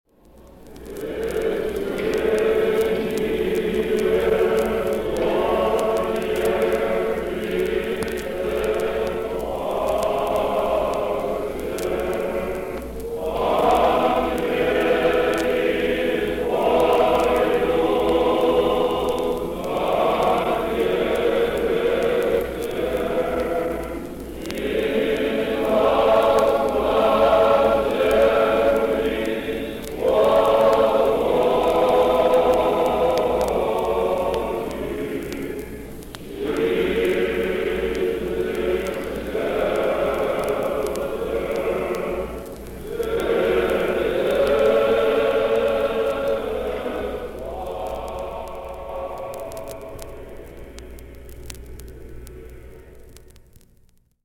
Служение Патриарха Алексия I-го. Великий Пост и Пасха 1962г.
(трио из Богоявленского Патриаршего собора)